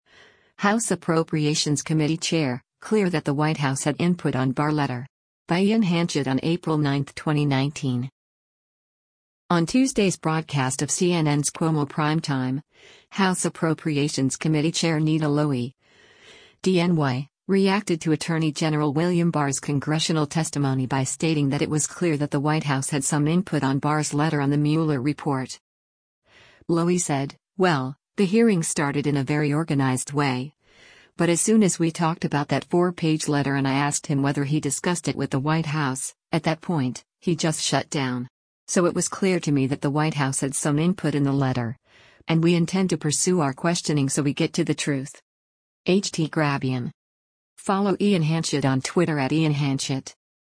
On Tuesday’s broadcast of CNN’s “Cuomo Primetime,” House Appropriations Committee Chair Nita Lowey (D-NY) reacted to Attorney General William Barr’s Congressional testimony by stating that “it was clear” that the White House “had some input” on Barr’s letter on the Mueller report.